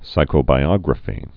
(sīkō-bī-ŏgrə-fē)